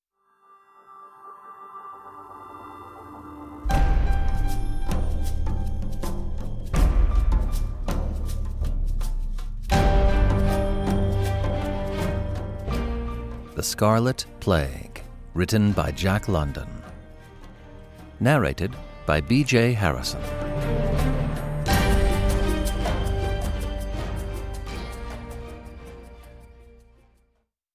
Ukázka z knihy